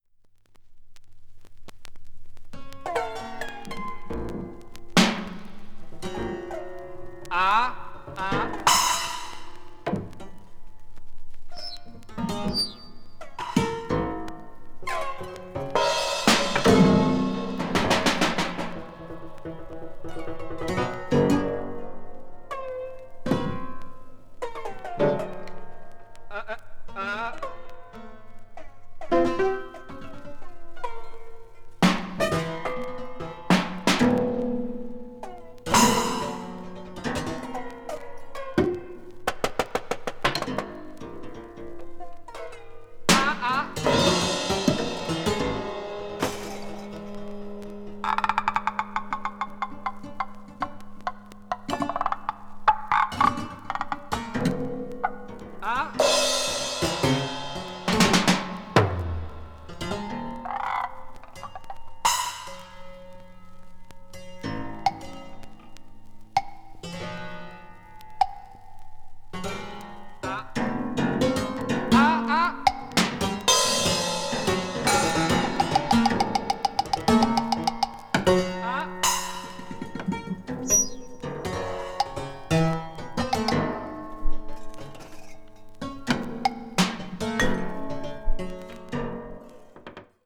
B面中盤でチノイズ数回、B面後半に横方向の傷があり、ジリっとした目立つノイズが入るところが1ヶ所ずつあります。